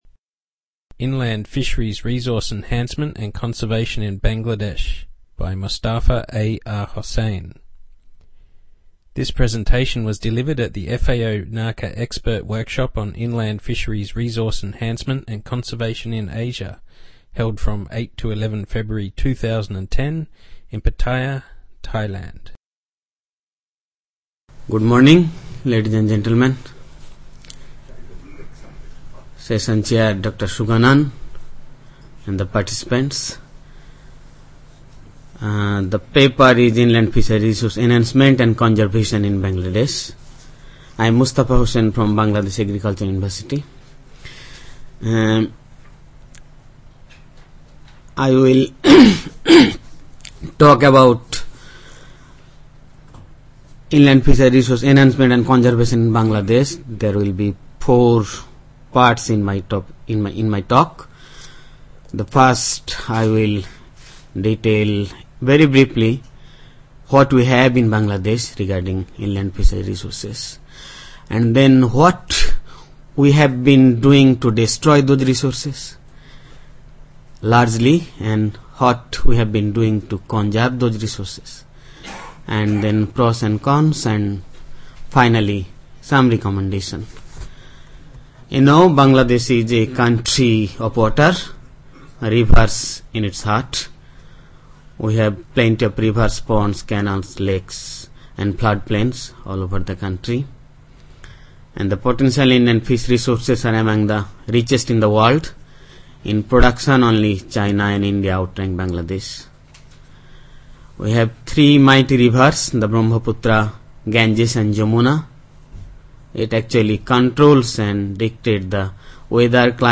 Presentation on inland fisheries resource enhancement and conservation in Bangladesh